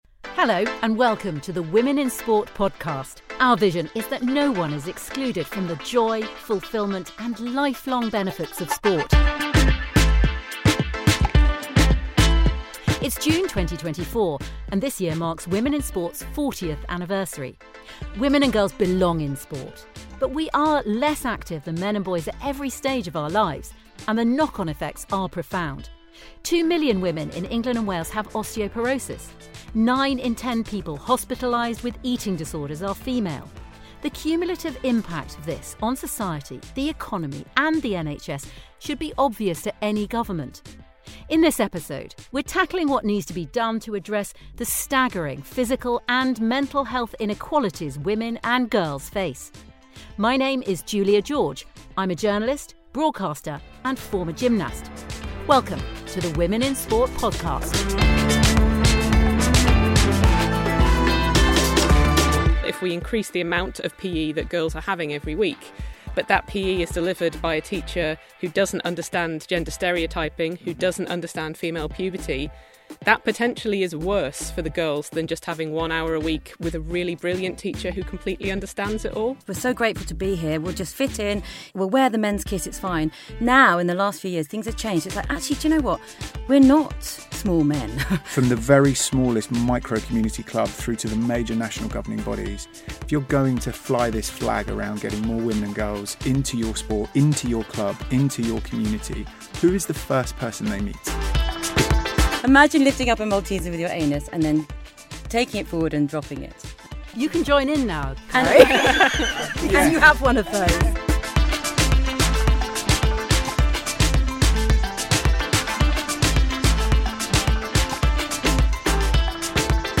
This episode of our podcast looks at how we help the UK step up and recognise the value and importance of sport to women and girls. [Warning: There is a section midway through this episode where there's a bit of swearing] Presenter